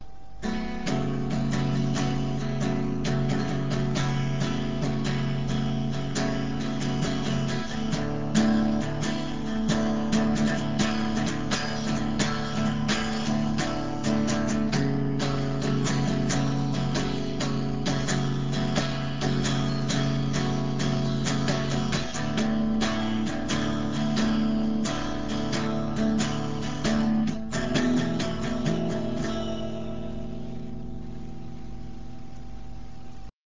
Spielweisen der  E-Gitarre
Plektrum-Spiel
Cleanes offenes spielen